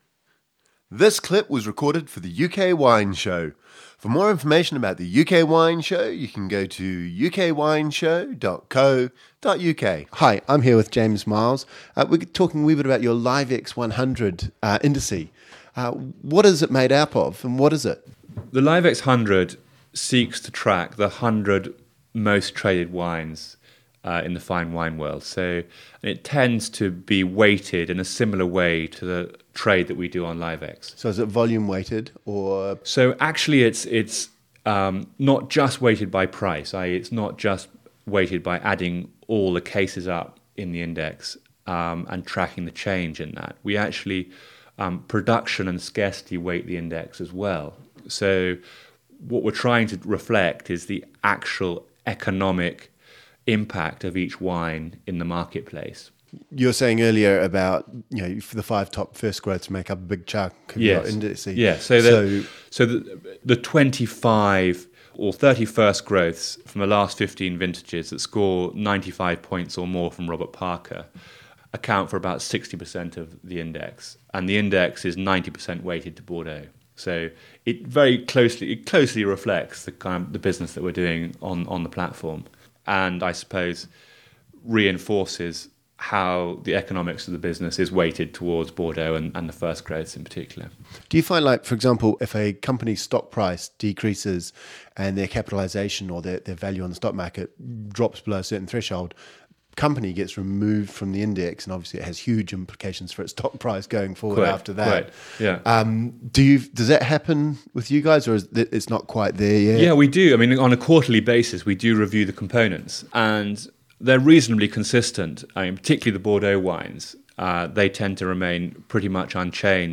In this final interview we talk about the Liv-ex 100 index. The Liv-ex 100 index tracks the 100 most traded wines in the fine wine world and is closely related to the trade on the Liv-ex fine wine exchange.